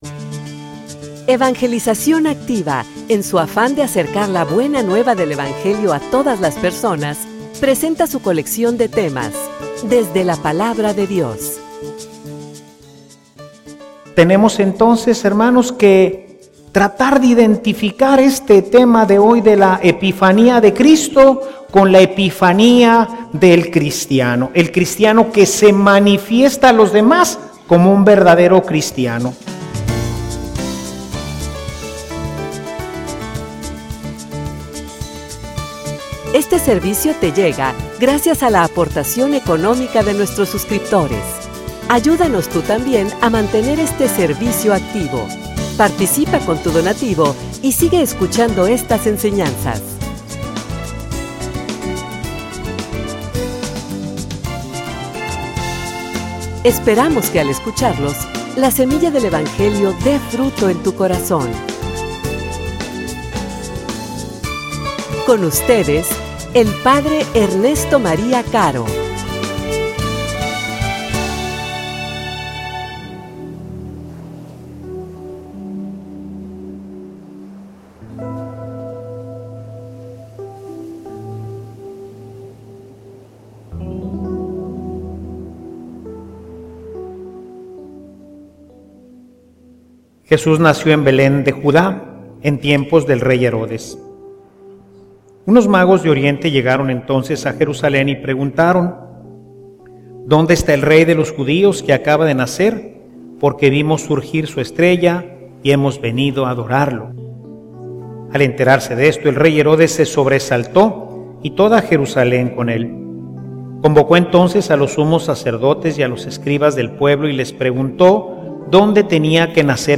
homilia_Se_te_nota.mp3